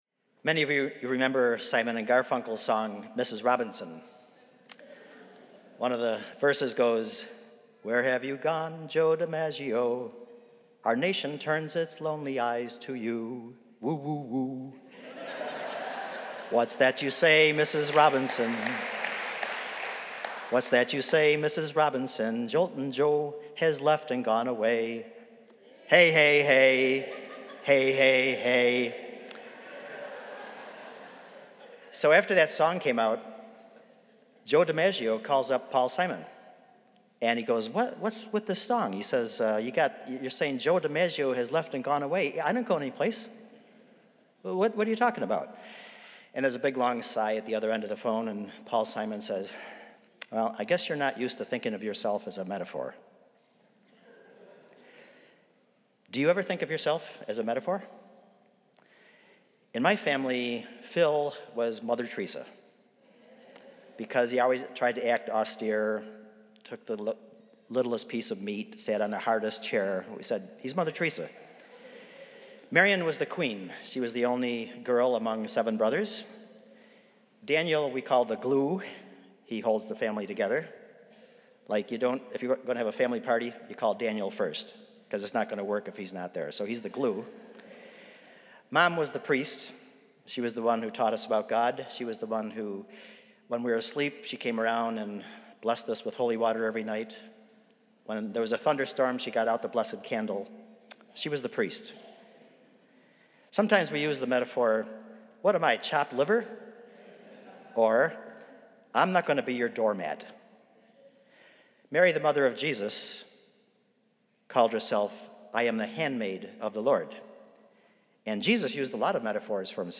Spiritus Christi Mass August 12th, 2018
The Spiritus Gospel Choir is singing.